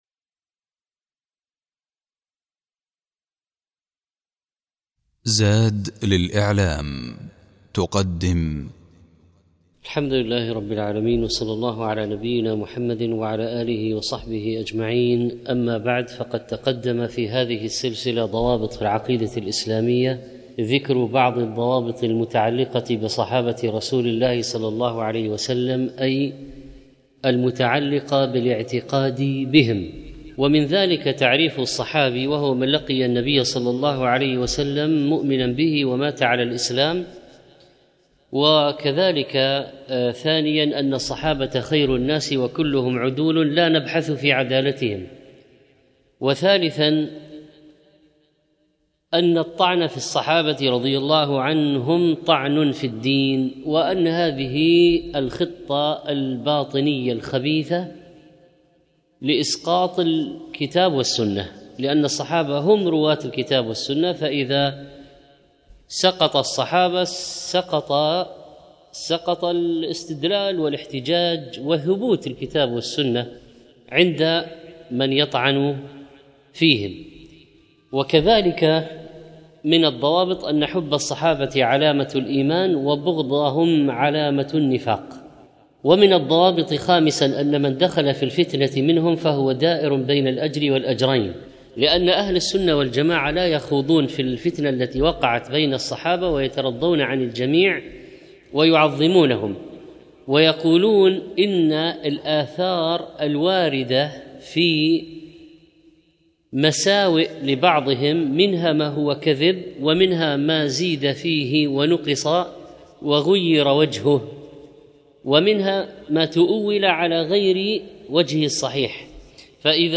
الموقع الرسمي للشيخ محمد صالح المنجد يحوي جميع الدروس العلمية والمحاضرات والخطب والبرامج التلفزيونية للشيخ